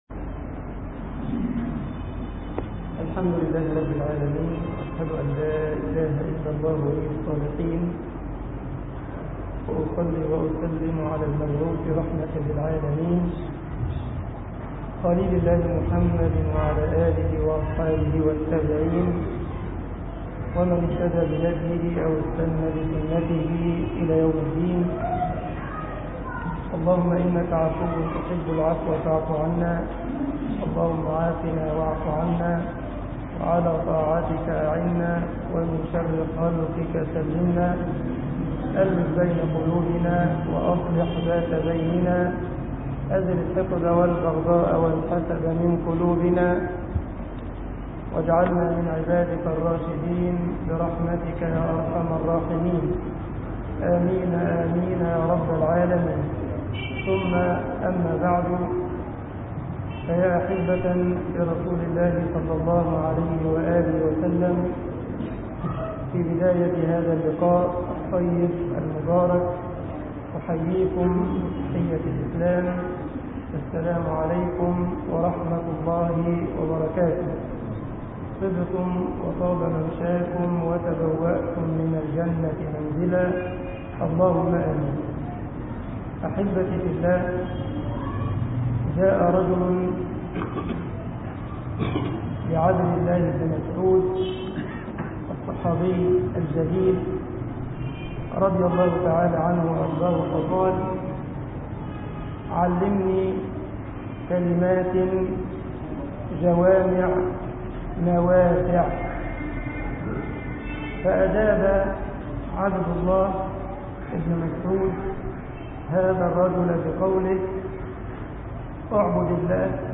كلمات جوامع نوافع طباعة البريد الإلكتروني التفاصيل كتب بواسطة: admin المجموعة: مواضيع مختلفة - رقائق Download مسجد عمر بن الخطاب ـ عمرانية غربية ـ جيزة التفاصيل نشر بتاريخ: الأربعاء، 06 تشرين1/أكتوير 2010 21:50 الزيارات: 4136 السابق التالي